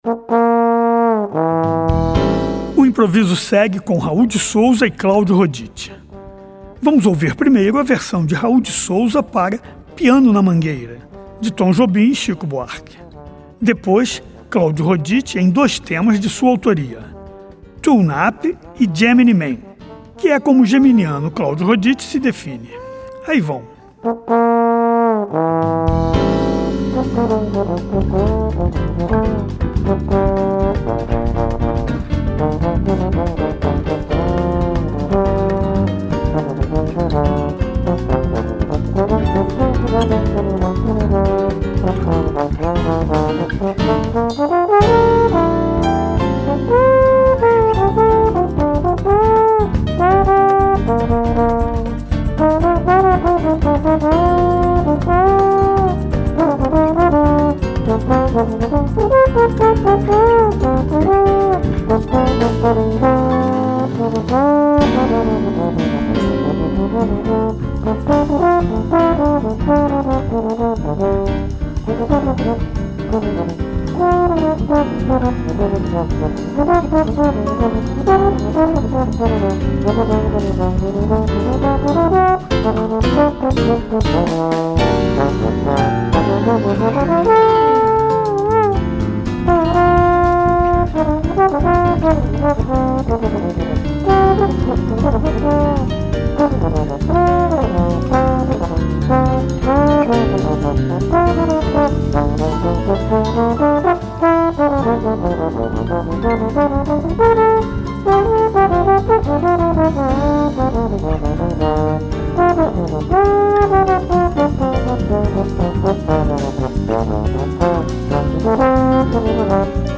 samba jazz